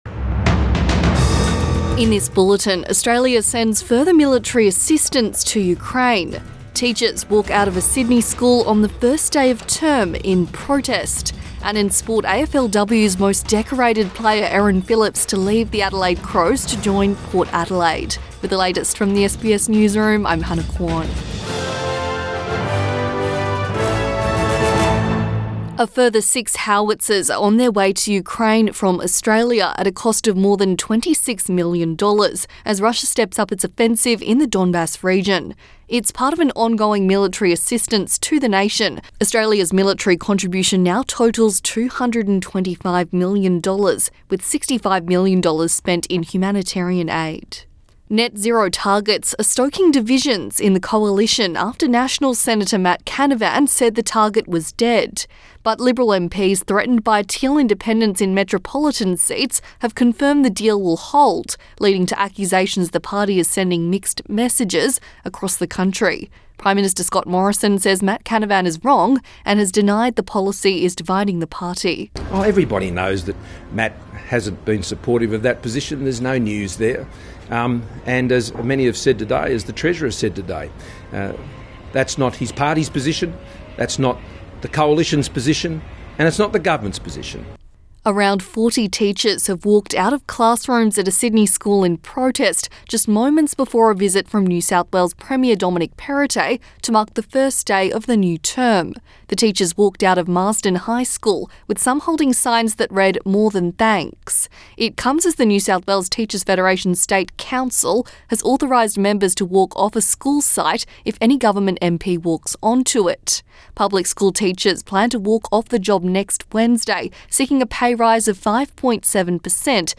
Midday bulletin 27 April 2022